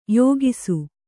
♪ yōgisu